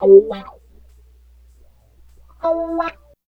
88 GTR 1  -L.wav